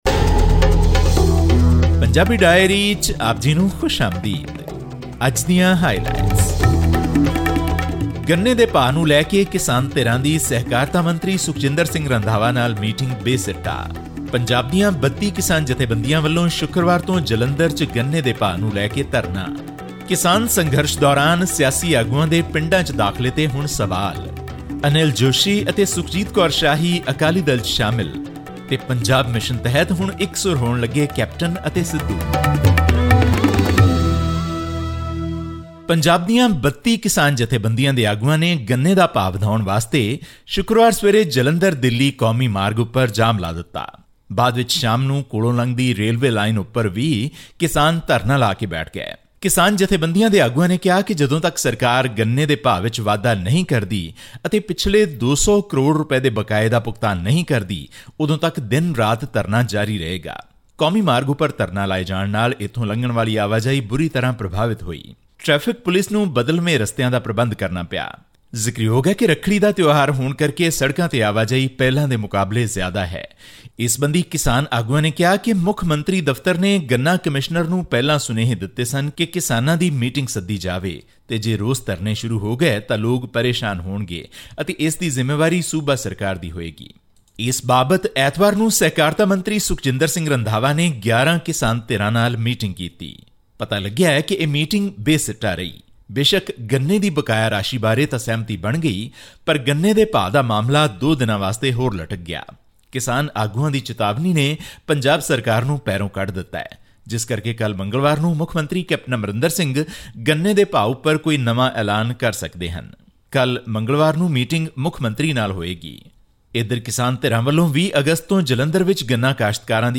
Farmers in Punjab have blocked the Delhi-Amritsar national highway near Jalandhar, along with the rail tracks, over their demand for raising the State Assured Price (SAP) of sugarcane. They have already rejected the hike of Rs 15 per quintal. All this and more in our weekly news update from Punjab.